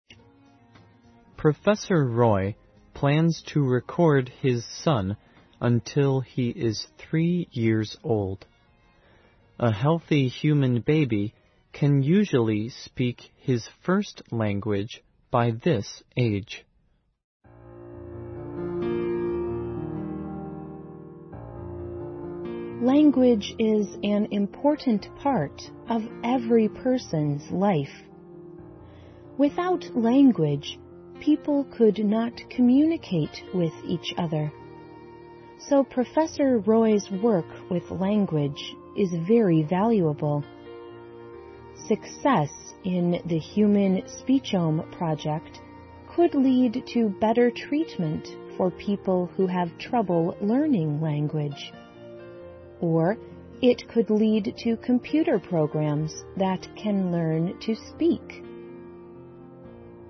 环球慢速英语 第557期:人类家庭语言计划(8)